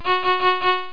dingding.mp3